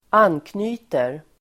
Uttal: [²'an:kny:ter]